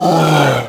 c_nemesis_hit1.wav